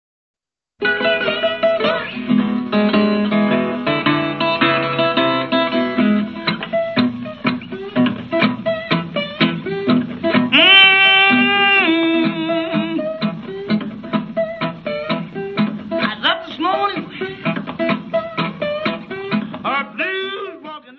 : stereo; 12 cm
Área:  Jazz / Blues